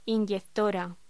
Locución: Inyectora
voz